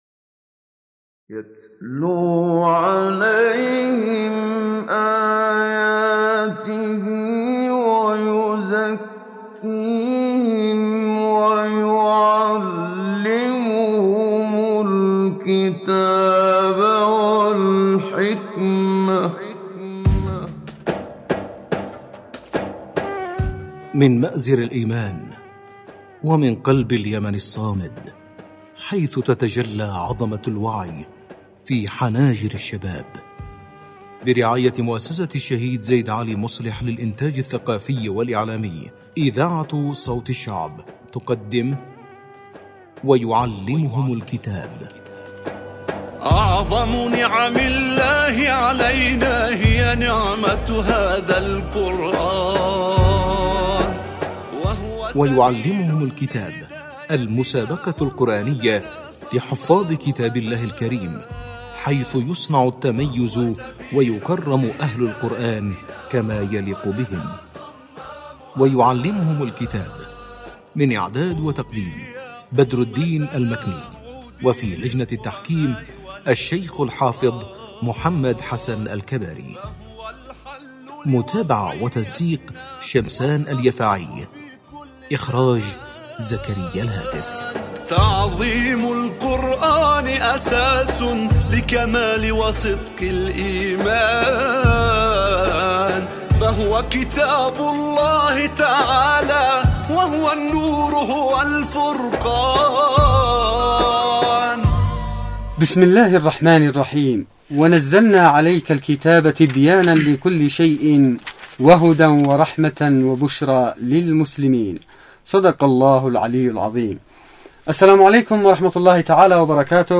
مسابقة_القرآن_الكريم_ويعلمهم_الكتاب_13.mp3